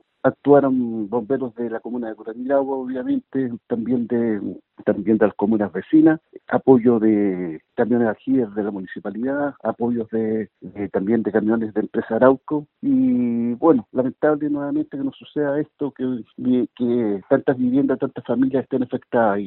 Bomberos de Cañete, Arauco, Lebu y Los Álamos se trasladaron al lugar. Así lo detalló el alcalde de Curanilahue, Luis Gengnagel, quien además lamentó lo sucedido.